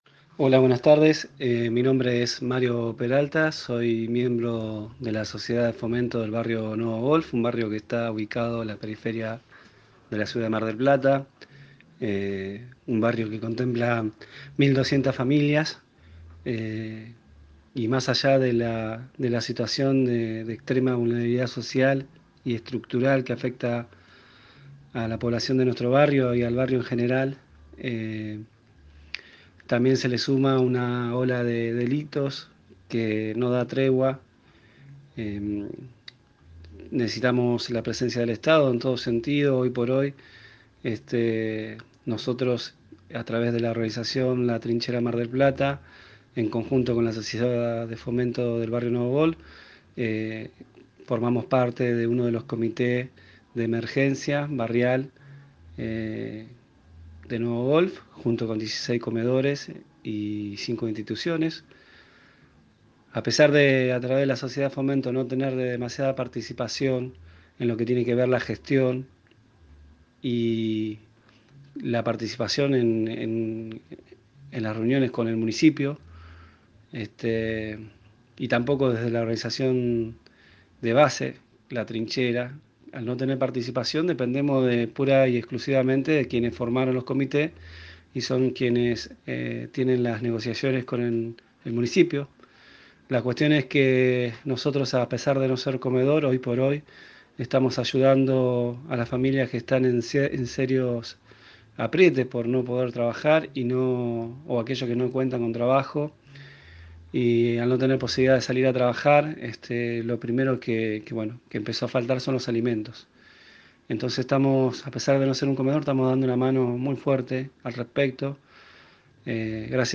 Los vecinos de Nuevo Golf en una recorrida que hicimos de voces nos cuentan las problemáticas que sufren en el barrio, en donde pasa el tiempo y, con el crecimiento natural del barrio, las carencias y ausencia del estado municipal cada vez se visibilizan más.